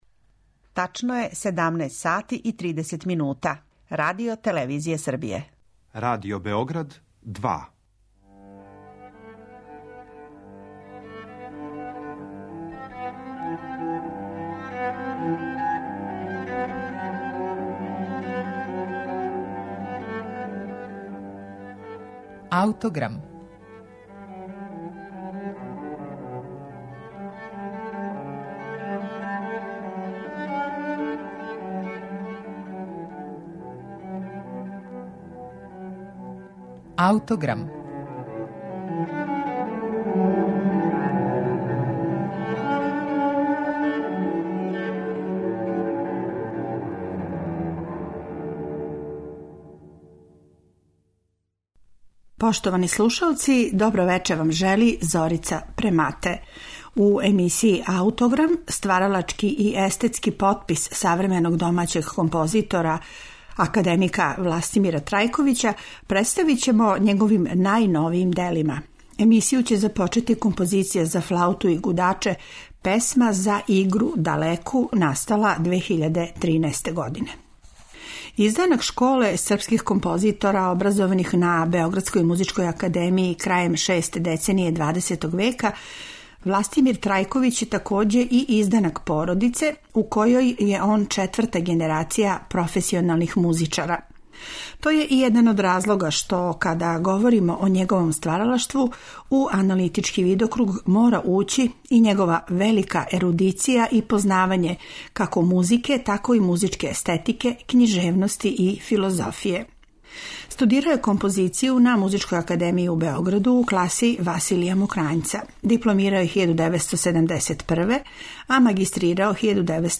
Снимак је са премијерног извођења, у салону Белог двора на Дедињу, 24. септембра 2013. године.